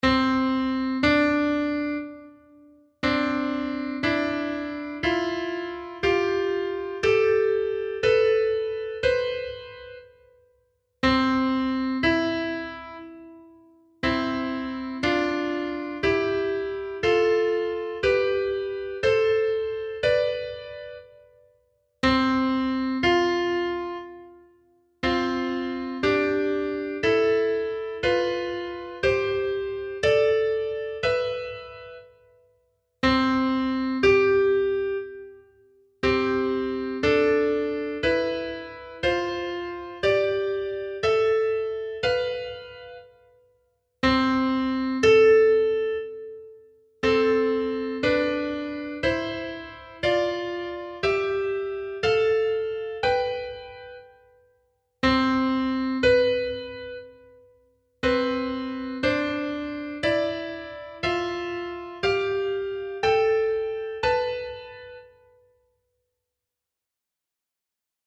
harmonicmajor
Harmonic Major scale.mp3